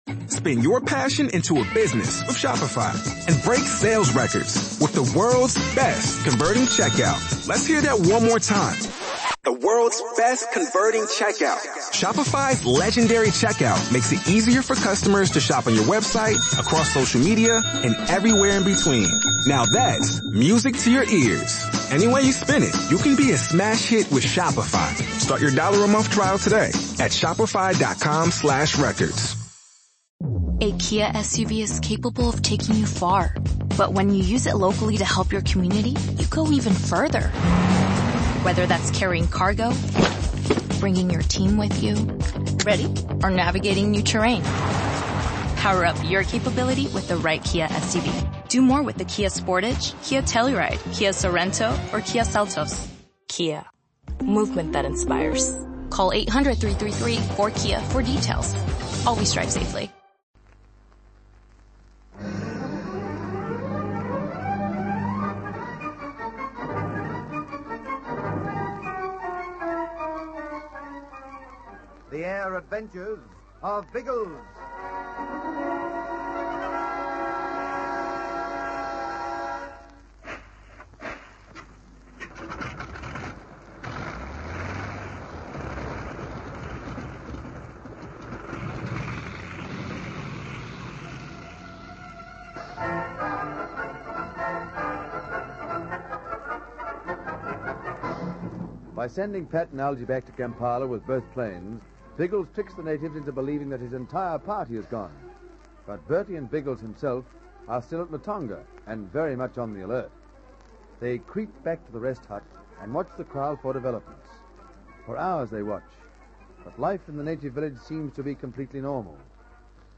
The Air Adventures of Biggles was a popular radio show that ran for almost a decade in Australia, from 1945 to 1954. It was based on the children's adventure novels of the same name by W.E. Johns, which chronicled the exploits of Major James Bigglesworth, a World War I flying ace who continued to have thrilling adventures in the years that followed.